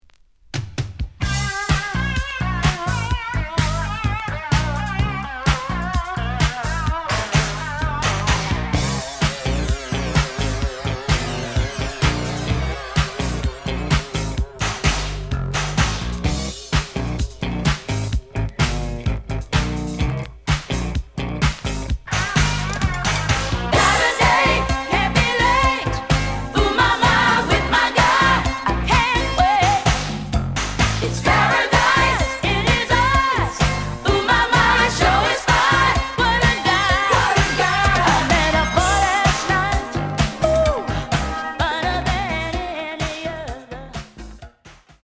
The audio sample is recorded from the actual item.
●Genre: Disco
Looks good, but slight noise on both sides.)